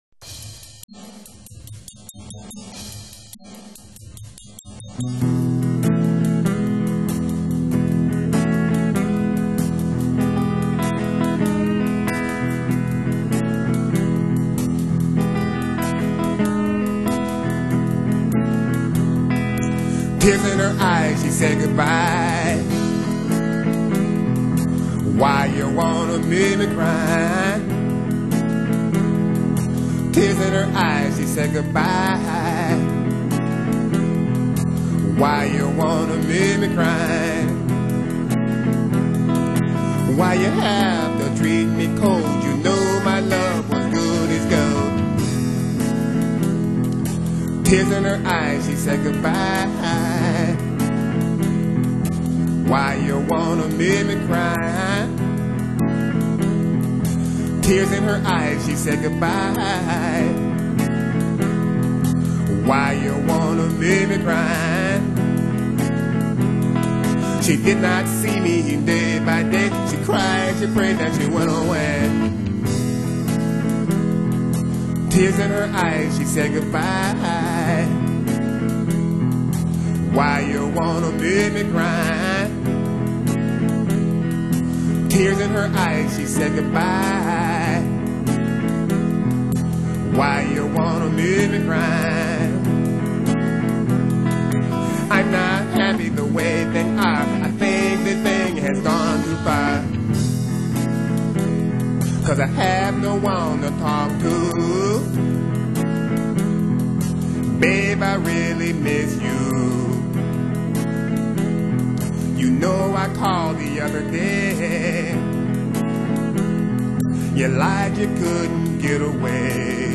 songwriter, guitarist, singer
playing the guitar and singing passionately